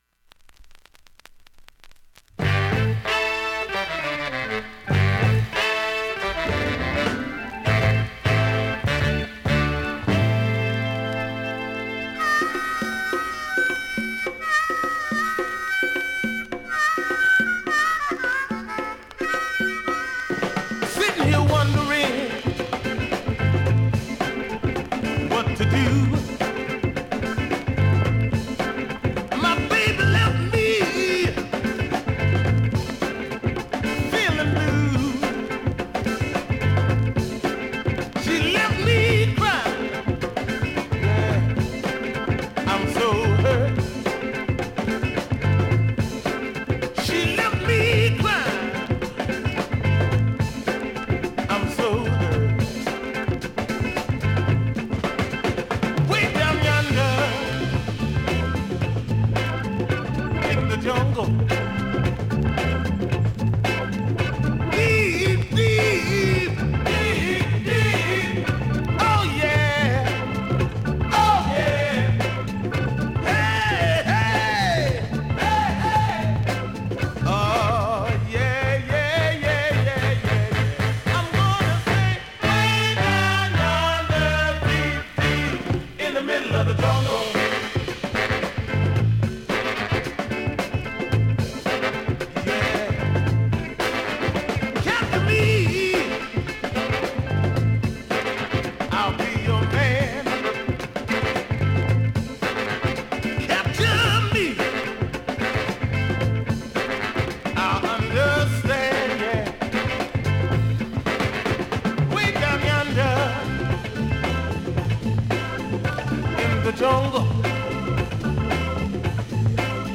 現物の試聴（両面すべて録音時間5分54秒）できます。